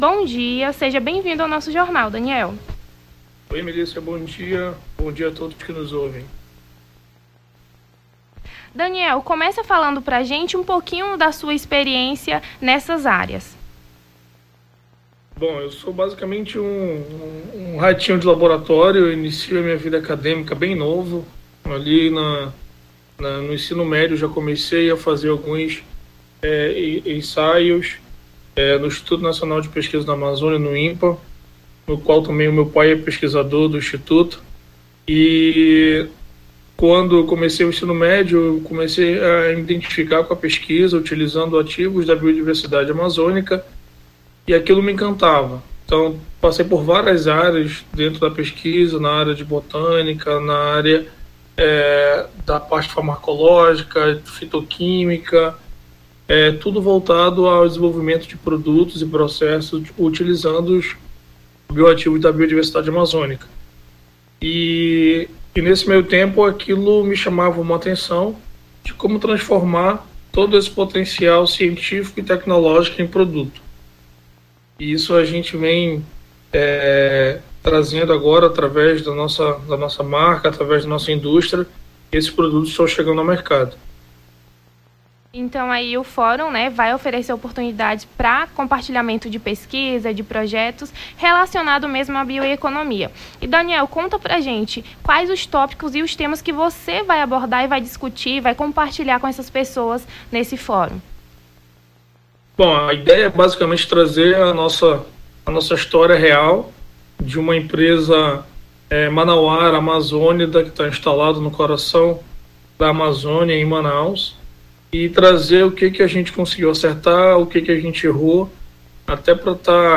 Nome do Artista - CENSURA - ENTREVISTA (FORUM BIOECONOMIA) 21-08-23.mp3